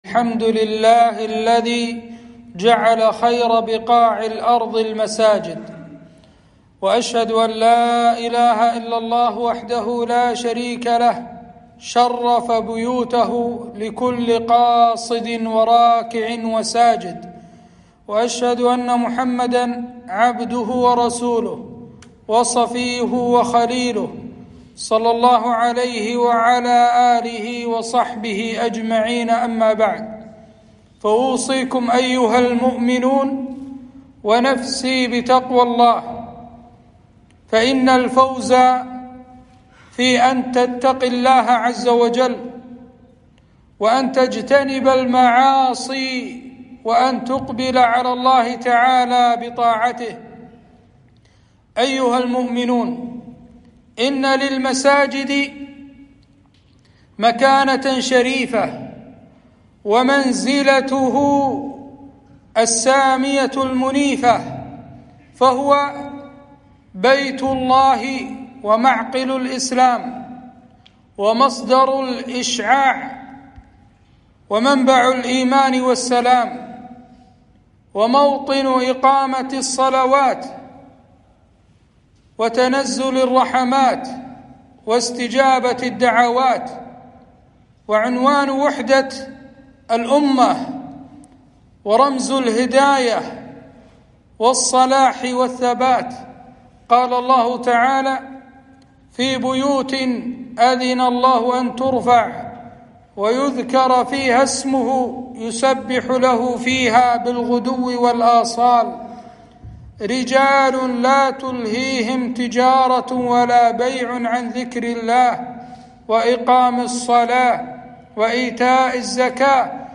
خطبة - فضل المساجد ومكانتها